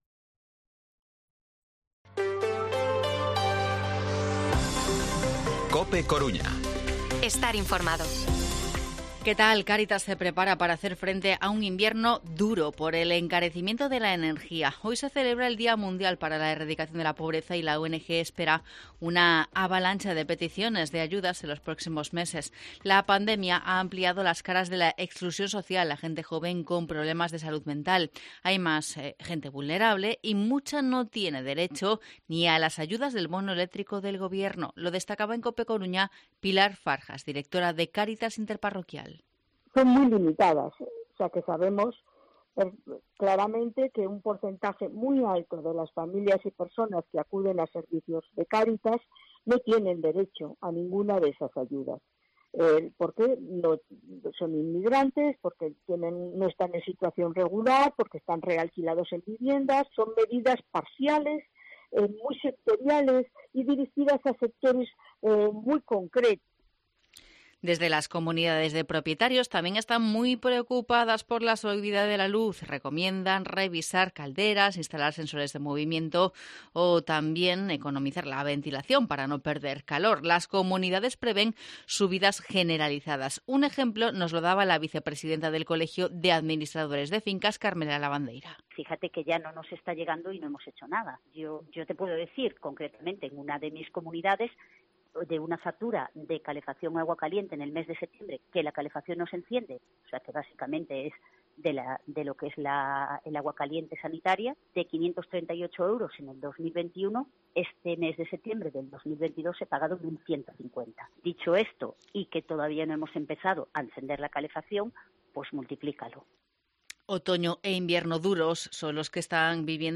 Informativo Mediodía COPE Coruña lunes, 17 de octubre de 2022 14:20-14:30